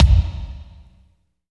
29.06 KICK.wav